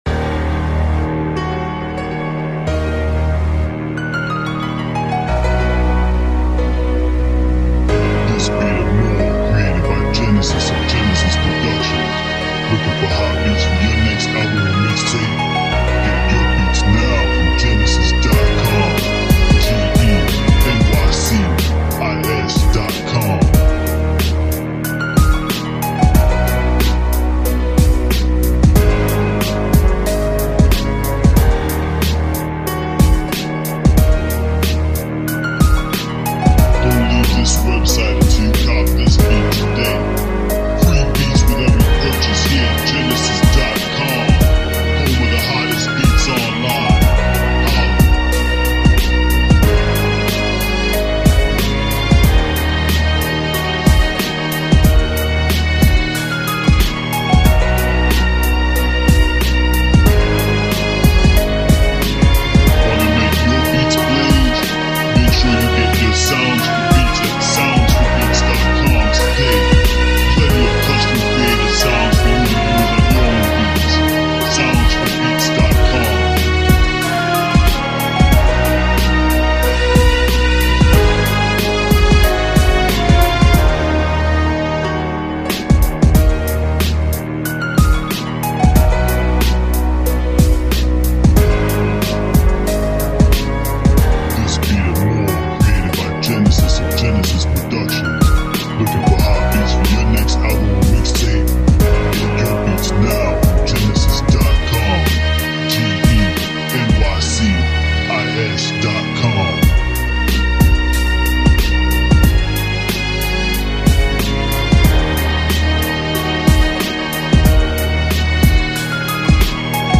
Storytelling Beats